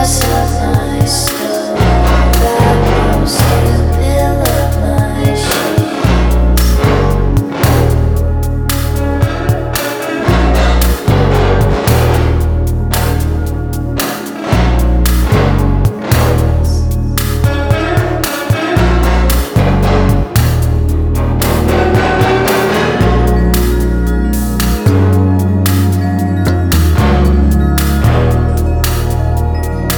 Indie Pop Alternative Pop
Жанр: Поп музыка / Альтернатива